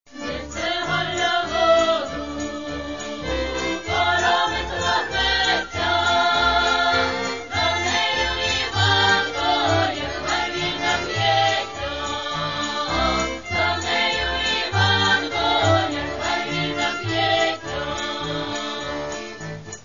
Catalogue -> Folk -> Traditional Singing